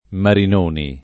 [ marin 1 ni ]